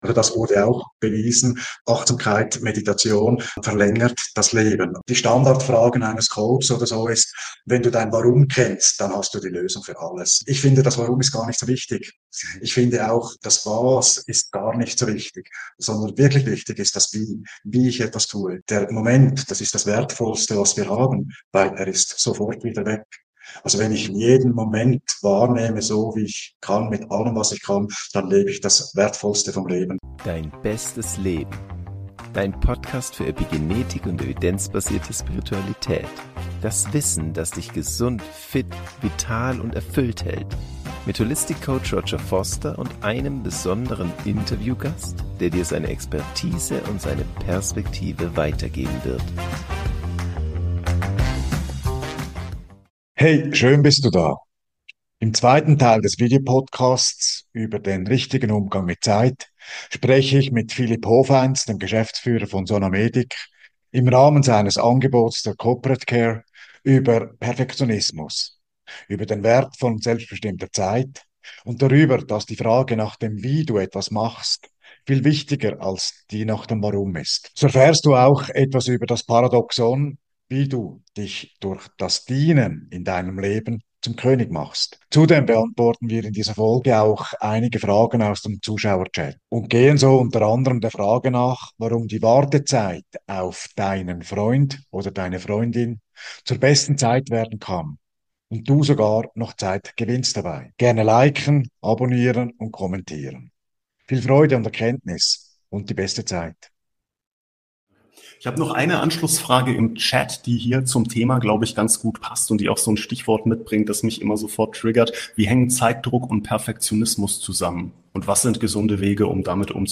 Das Interview